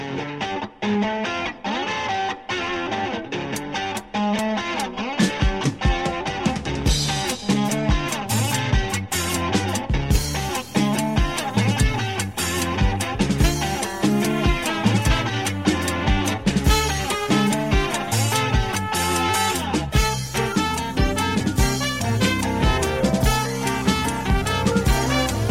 ска какоето по стилю)))
Подсказка: это что-то очень дряхленькое, старенькое